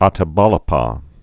tə-bälĭ-pä)